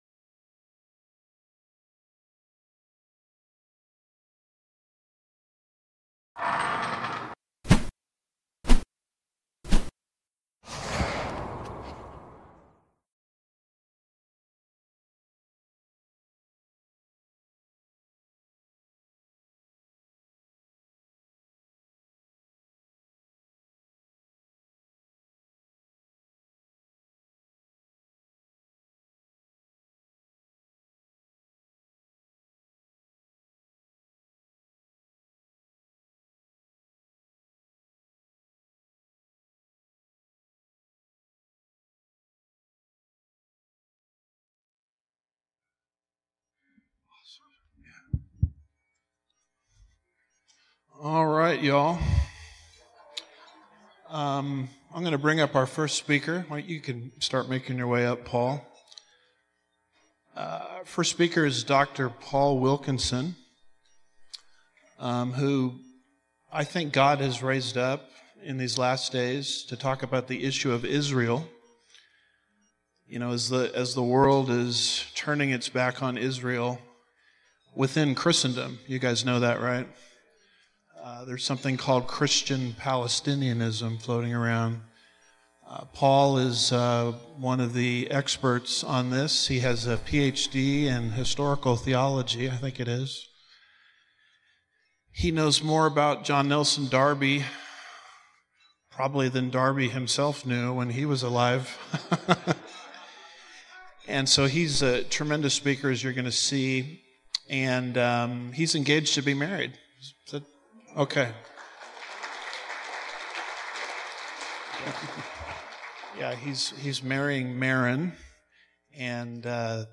2026 Prophecy Conference